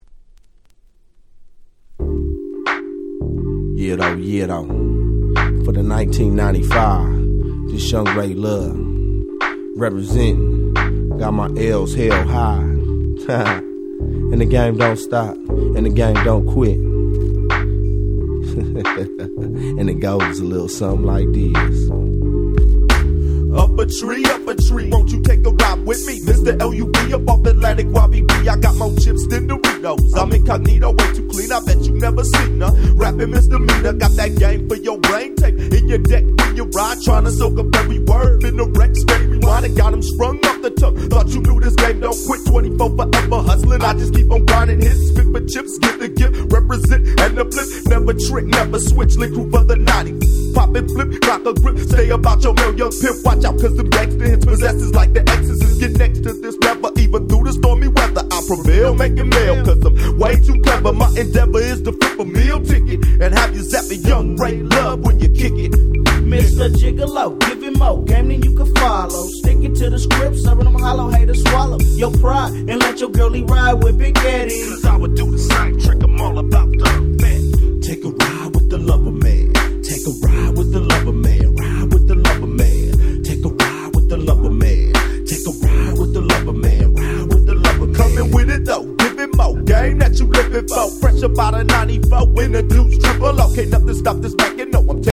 95' Nice West Coast Hip Hop / Gangsta Rap !!
ずっしりとした緩いBeatにピーヒャラシンセが堪らなくワル！！